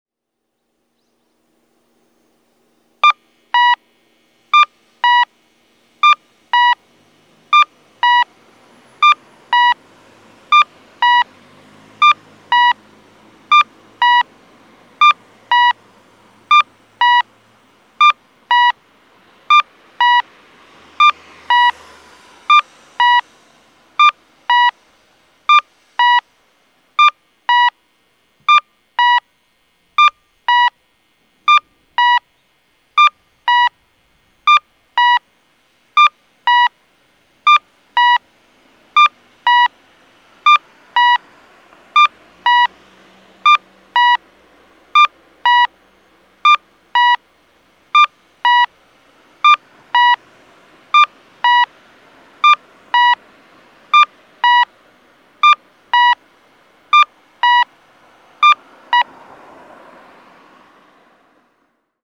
細野(和歌山県西牟婁郡白浜町)の音響信号を紹介しています。